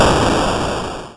atari_boom.ogg